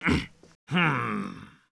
fishing_fail_v.wav